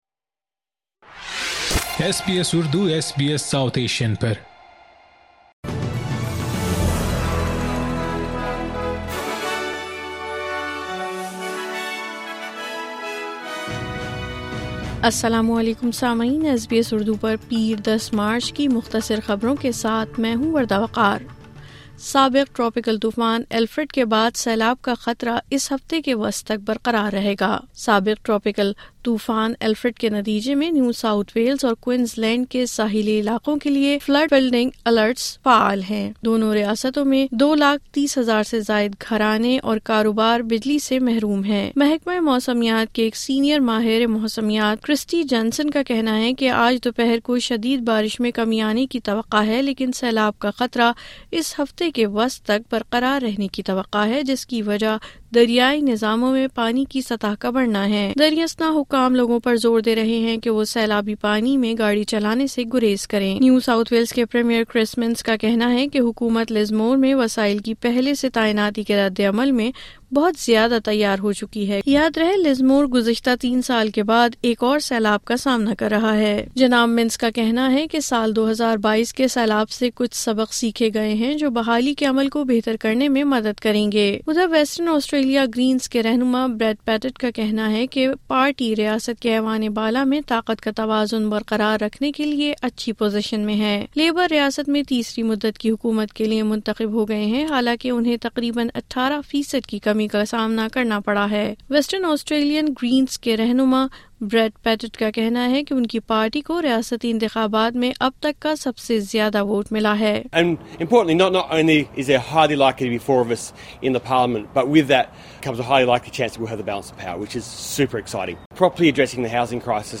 مختصر خبریں: پیر 10 مارچ 2025